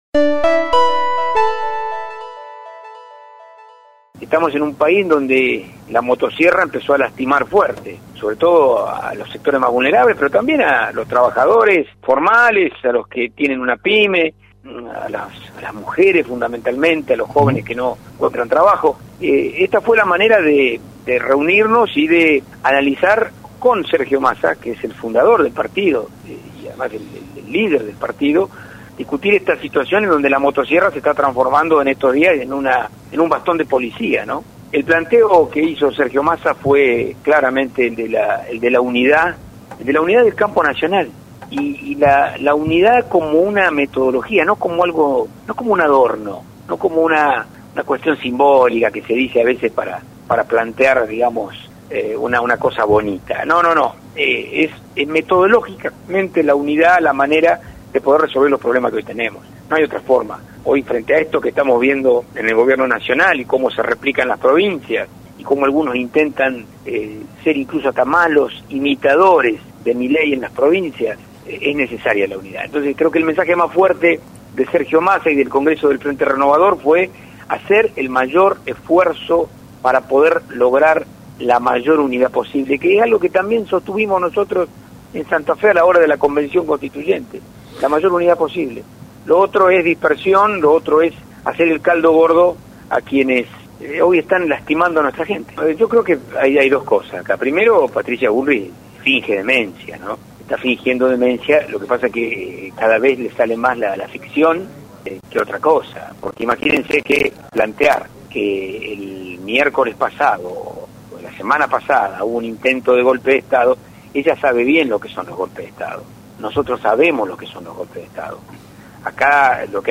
En diálogo con La Barra de Casal por LT3 , Diego Giuliano , presidente del Frente Renovador y referente en Rosario , destacó la necesidad de fortalecer al peronismo ante el contexto actual.